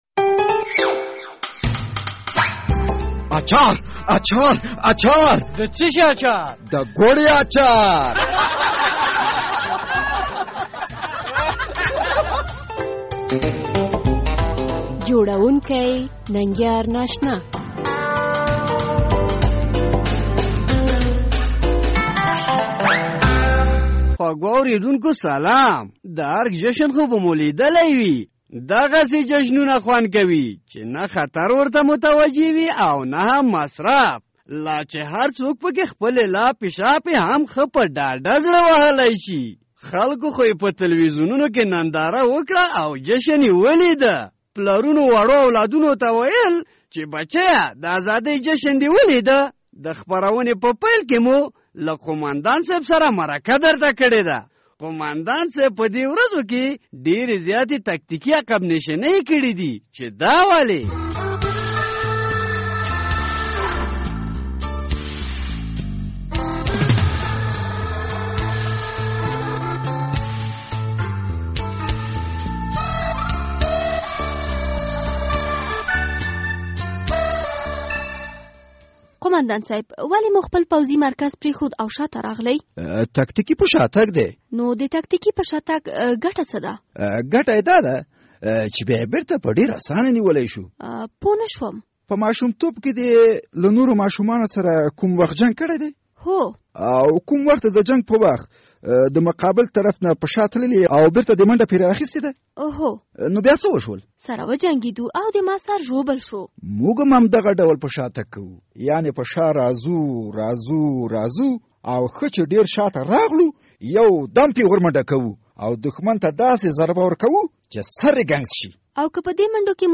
د ګوړې اچار په دې خپرونه کې لومړی د هغه قوماندان مرکه دراوروو چې ټول عمر ېې په تکتیکي عقب نشیني کې تېرشو...